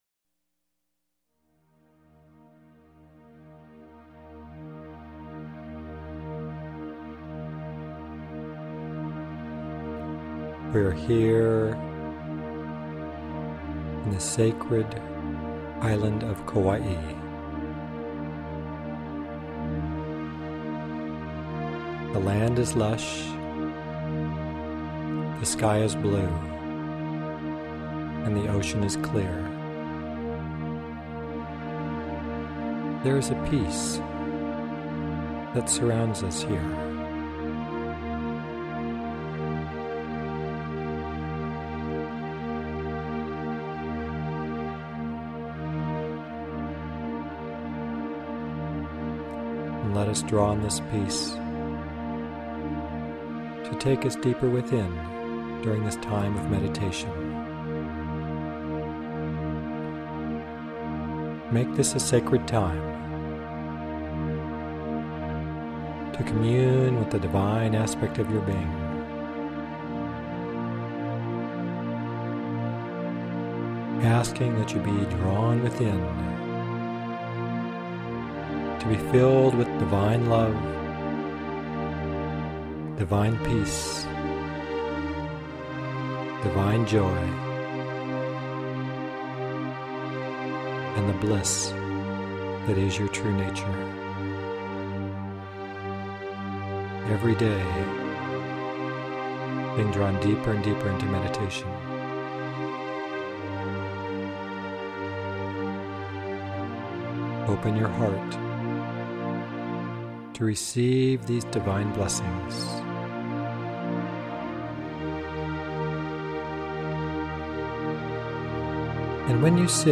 This guided meditation has been heard around the world. It can help you to awaken a conscious realization of your soul, open your heart, and expand your sense of love.
chakra_meditation_full_ed_01.mp3